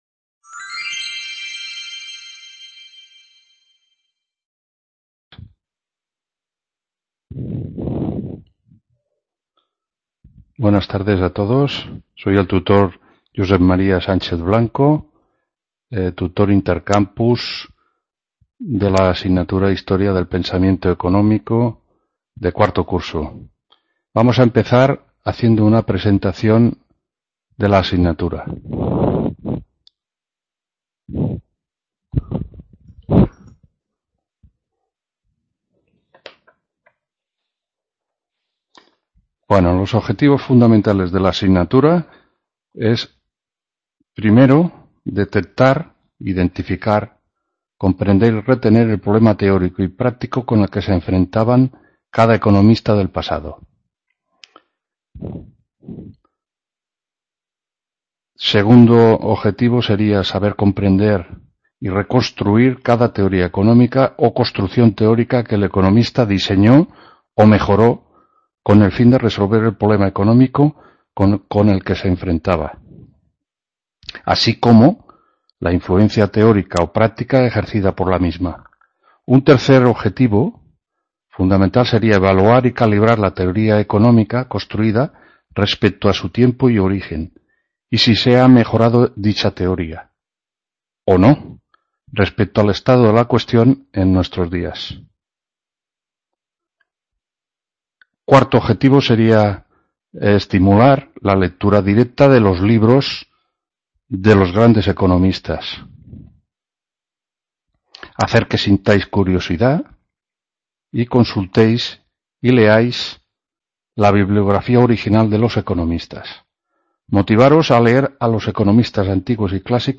1ª TUTORÍA INTERCAMPUS HISTORIA DEL PENSAMIENTO… | Repositorio Digital